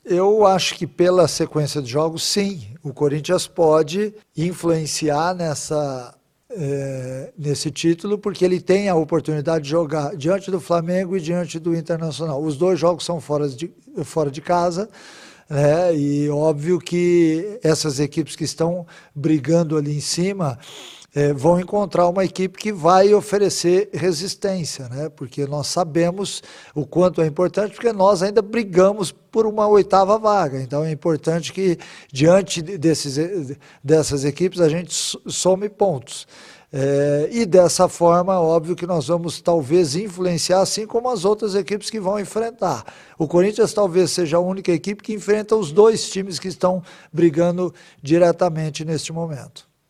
Em entrevista coletiva, o técnico Vagner Mancini falou sobre essa sequência da equipe no Brasileirão e lembrou que os adversários que estão na briga pelo título brasileiro terão dificuldades contra o Corinthians, uma vez que a equipe paulista ainda briga por uma vaga na próxima edição da Libertadores.